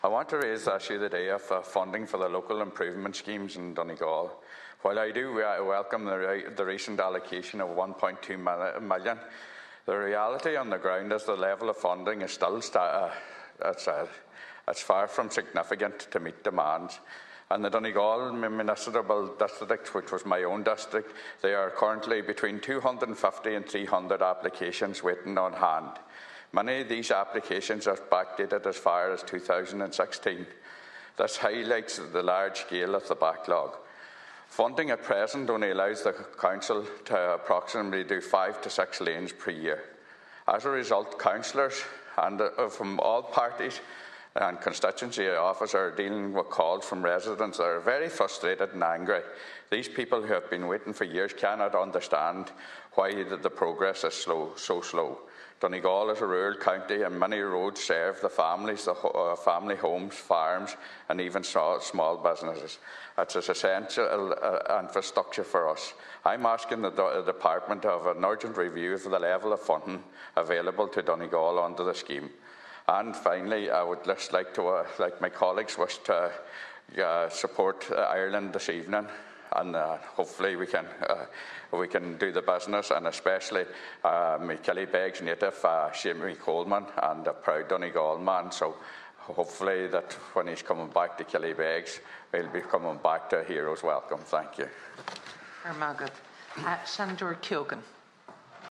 During a sitting of the Seanad Senator Manus Boyle welcomed LIS funding but called it far from significant
Senator Boyle highlights the extent of the application backlog in one Municipal District: